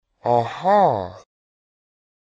Звуки человека, аха
• Качество: высокое
Аха мужчина все понял